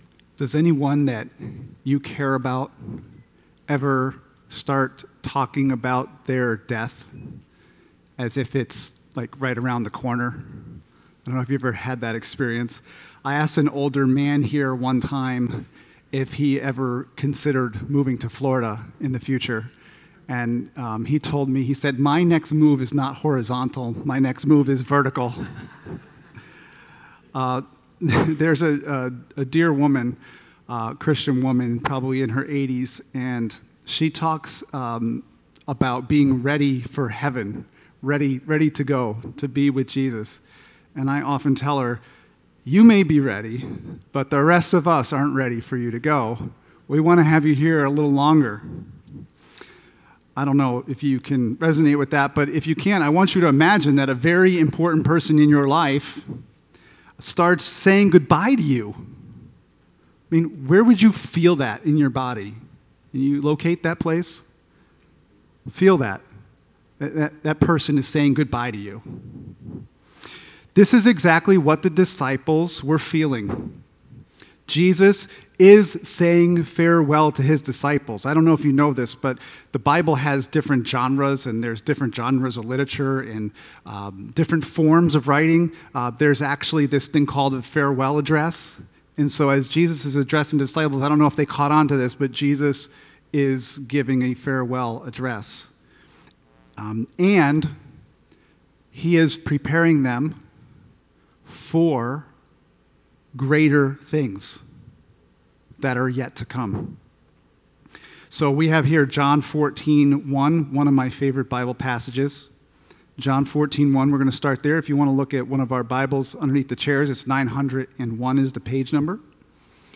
( Sunday AM )